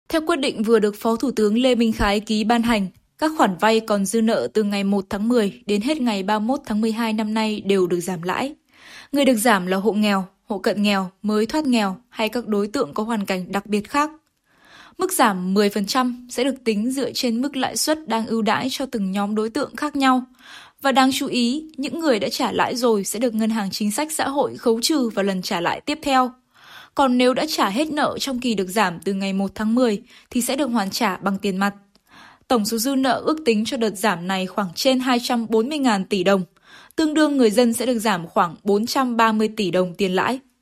宣传片解说